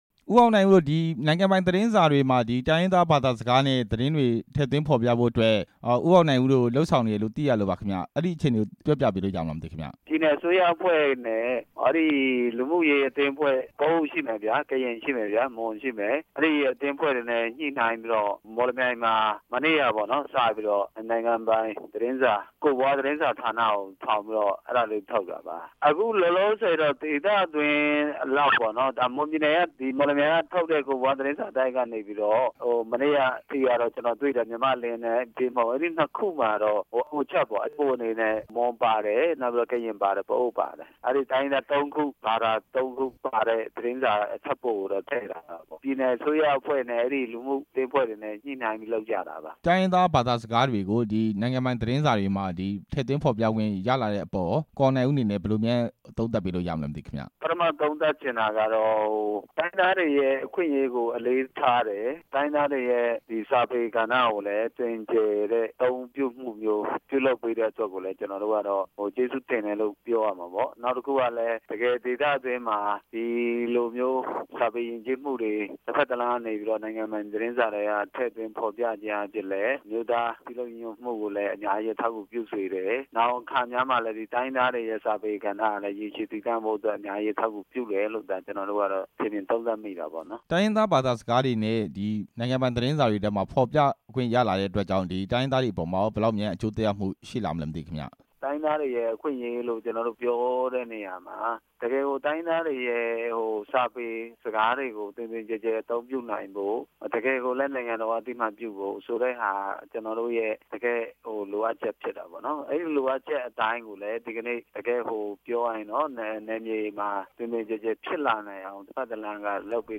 လွှတ်တော်ကိုယ်စားလှယ် ကိုအောင်နိုင်ဦးနဲ့ မေးမြန်းချက်